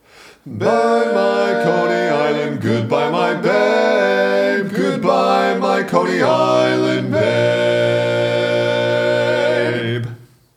Other part 4: